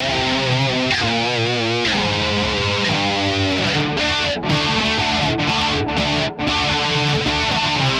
90年代的STRATOCASTER墨西哥ARPEGGIO
标签： 125 bpm Grunge Loops Guitar Electric Loops 2.58 MB wav Key : Unknown
声道立体声